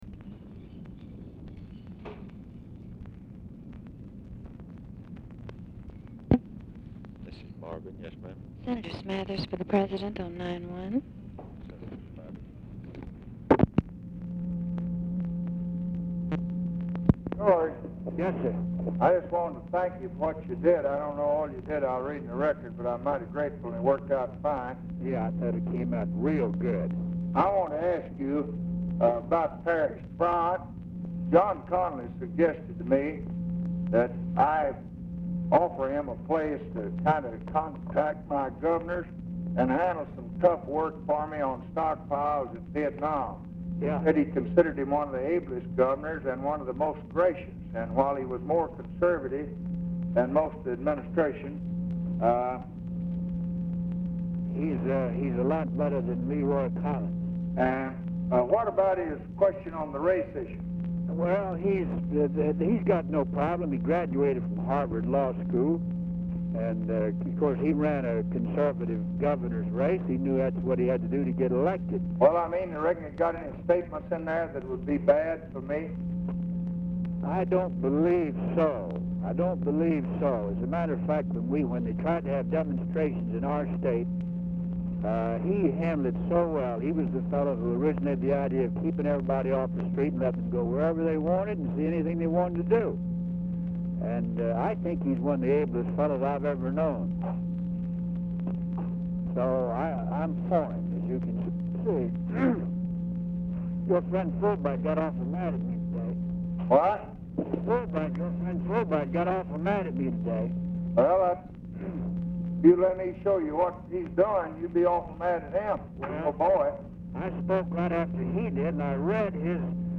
Telephone conversation # 9810, sound recording, LBJ and GEORGE SMATHERS, 3/1/1966, 7:15PM
RECORDING OF CONVERSATION IS BRIEFLY INTERRUPTED IN MIDDLE OF THE CALL; POOR SOUND QUALITY AT TIMES
Format Dictation belt